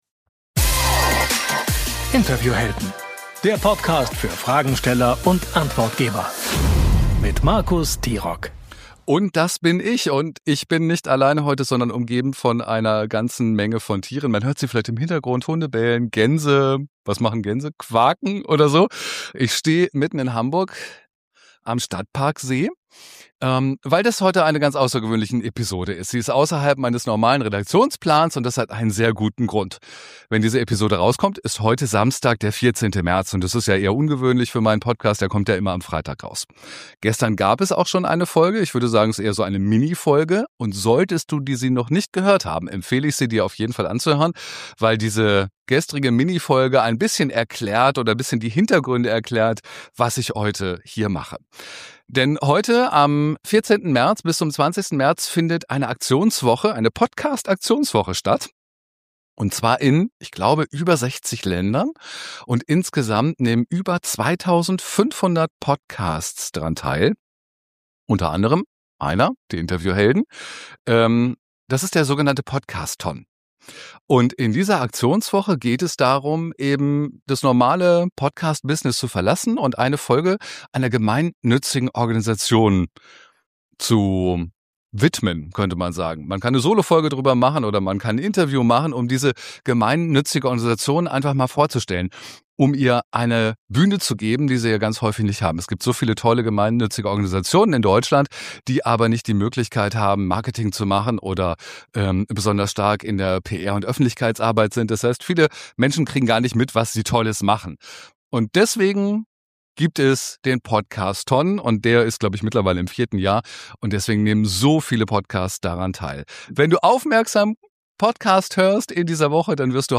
Wir reden über das Symbol hinter Welcoming Out, über Reaktionen, die zeigen, dass es funktioniert, über Rainbow-Washing, über Fragen und Aussagen, die gut gemeint sind und trotzdem wehtun – und über ein neues Projekt, von dem ich selbst erst kurz vor dieser Aufnahme erfahren habe. Diese Episode entstand im Rahmen des Podcasthon 2026 – einer weltweiten Aktionswoche, in der Podcasts gemeinnützigen Organisationen eine Bühne geben.